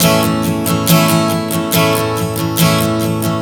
Strum 140 A 02.wav